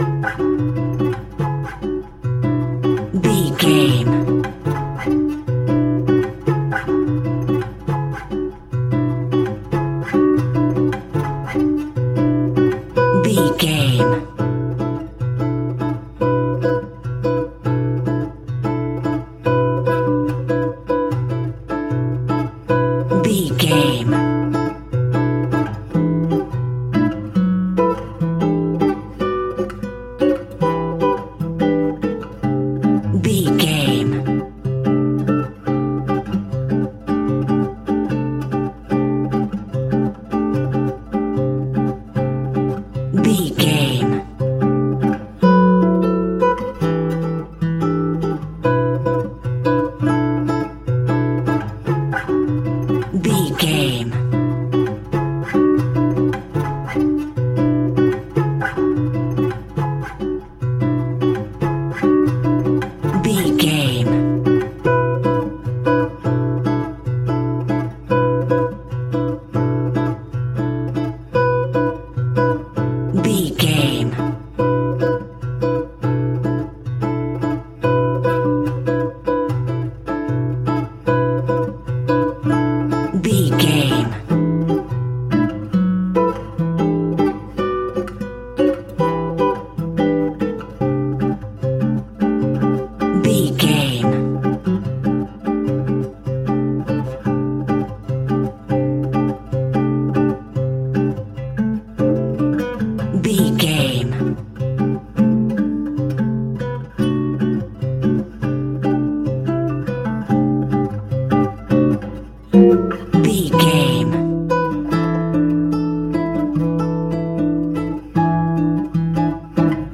Uplifting
Aeolian/Minor
C#
maracas
percussion spanish guitar